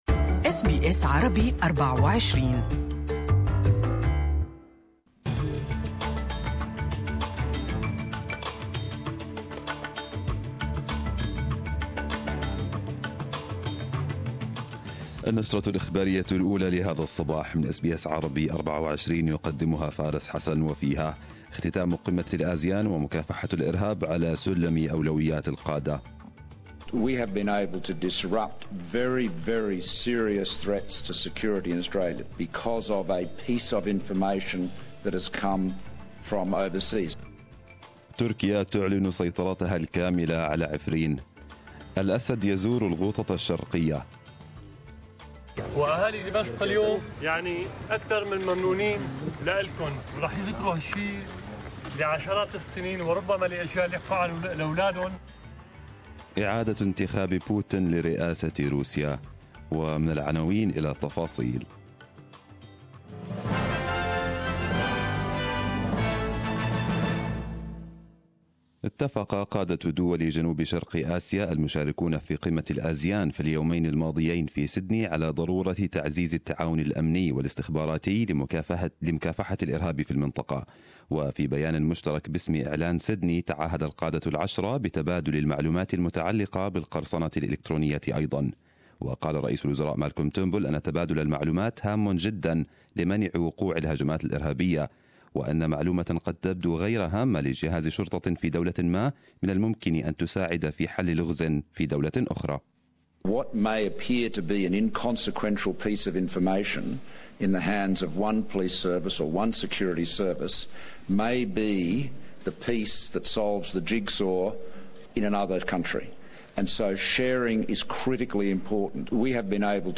Arabic News Bulletin 19/03/2018